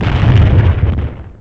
EXPLODE3.WAV